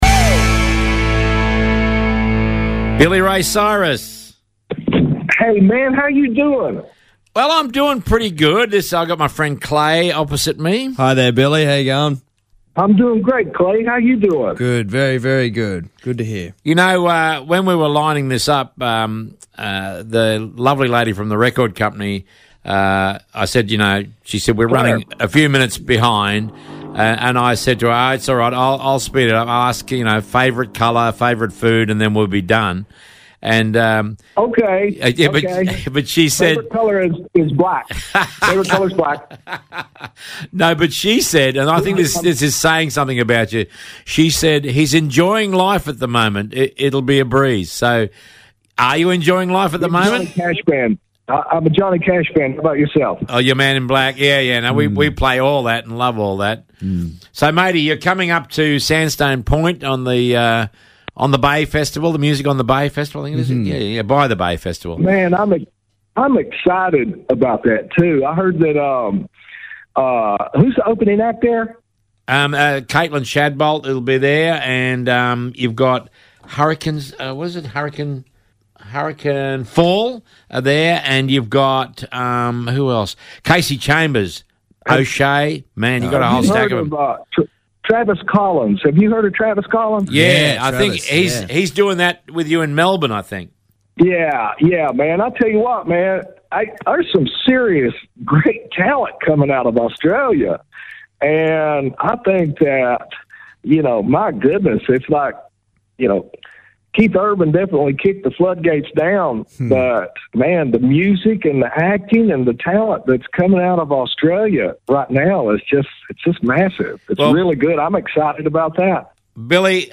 A GREAT INTERVIEW!!!!